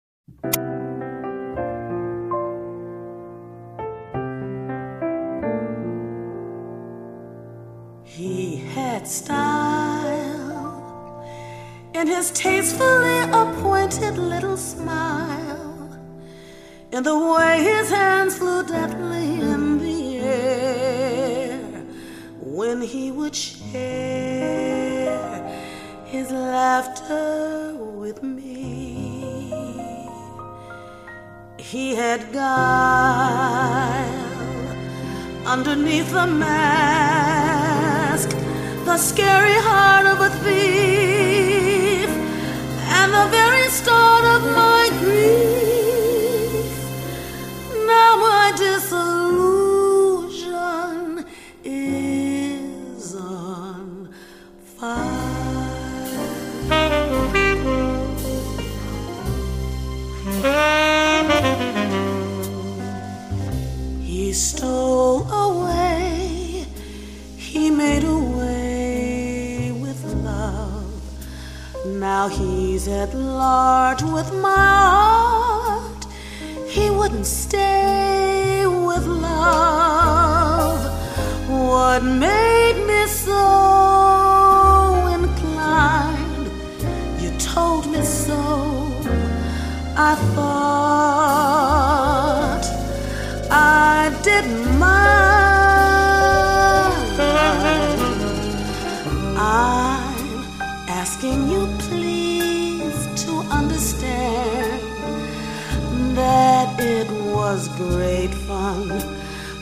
in Minneapolis